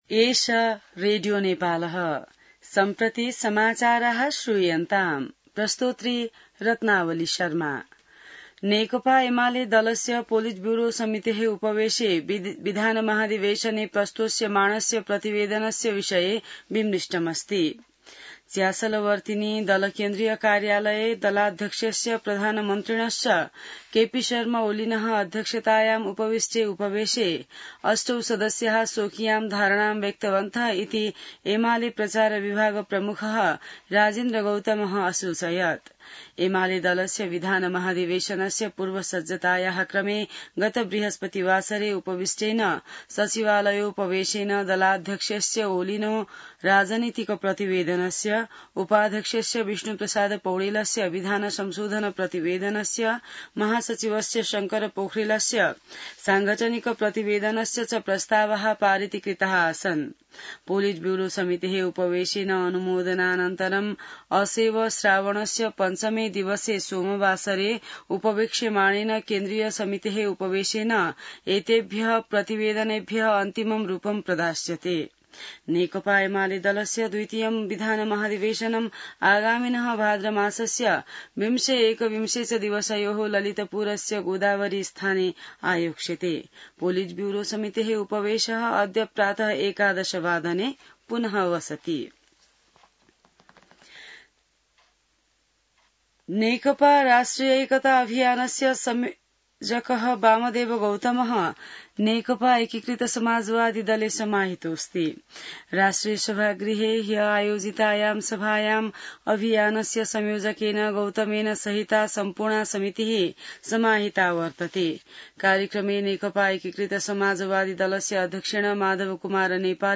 An online outlet of Nepal's national radio broadcaster
संस्कृत समाचार : ३ साउन , २०८२